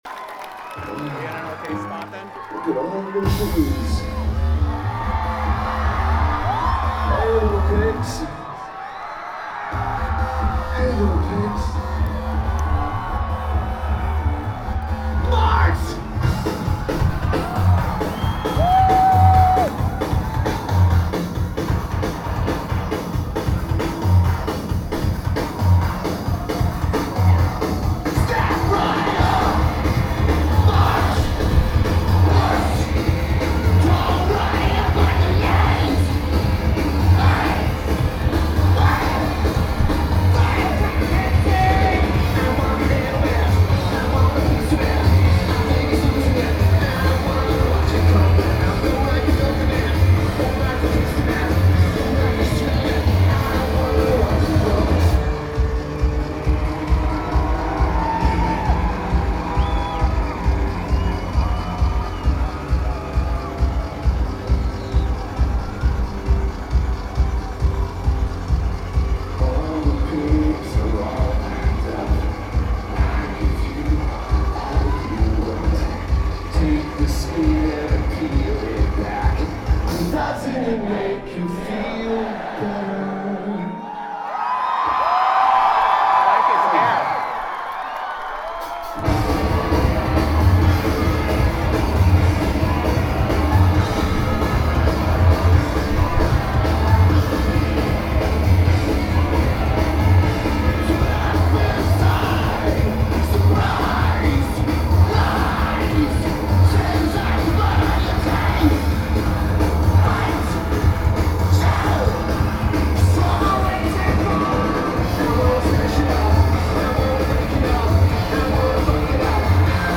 San Diego, CA United States
Lineage: Audio - AUD (Unknown DAT + Unknown Mics)